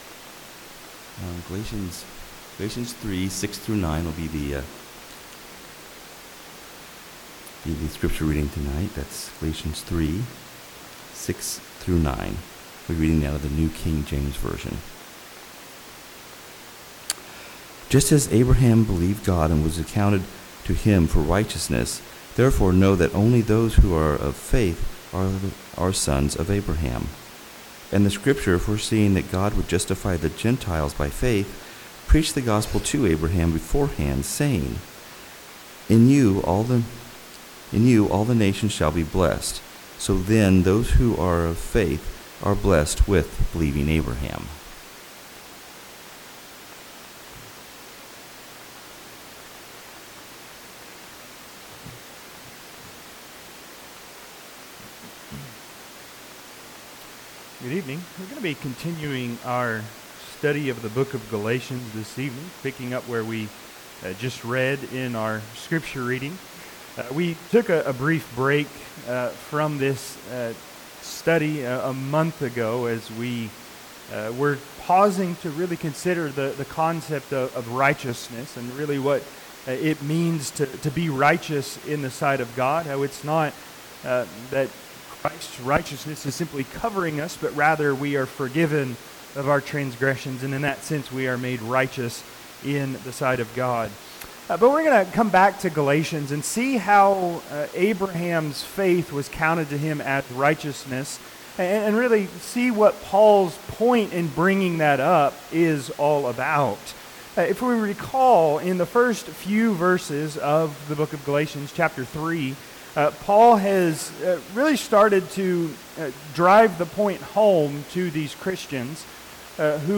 Passage: Galatians 3:6-9 Service Type: Sunday PM